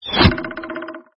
hit.mp3